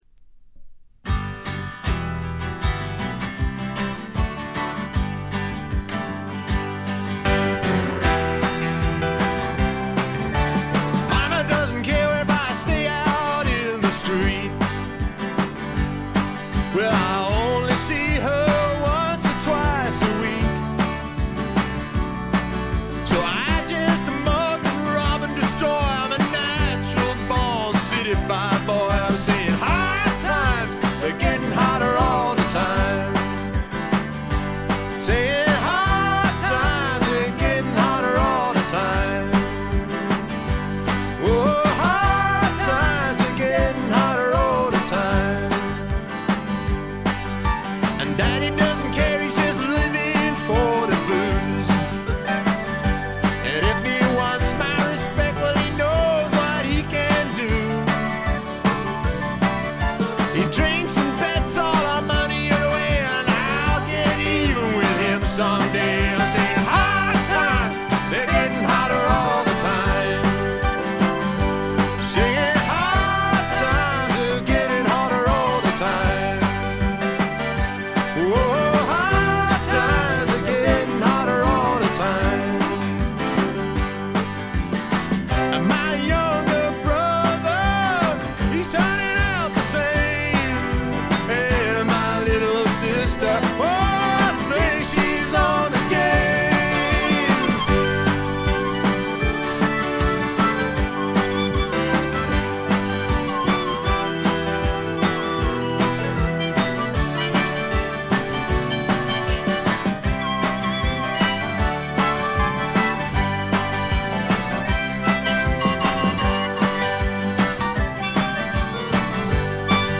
ごくわずかなノイズ感のみ。
試聴曲は現品からの取り込み音源です。
Vocals Guitars
Keyboards
Balalaika
Bass Vocals
Recorded at Turboways Studio, Lonbdon 1985/6.